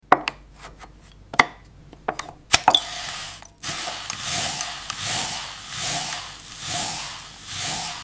A macro shot of a hand slowly sliding a translucent, pale-blue ice block onto a vintage hand-crank ice shaver. The ASMR is an ultra-crisp, resonant “shhh-crrr” as the steel blade scrapes the ice, with a faint, glassy ring after each cut.
Every flake lands in a small wooden bowl with a gentle, airy “puff” sound, followed by a barely audible twinkling chime.